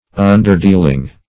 underdealing - definition of underdealing - synonyms, pronunciation, spelling from Free Dictionary
Search Result for " underdealing" : The Collaborative International Dictionary of English v.0.48: Underdealing \Un"der*deal`ing\, n. Crafty, unfair, or underhand dealing; unfair practice; trickery.